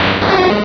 Cri d'Hyporoi dans Pokémon Rubis et Saphir.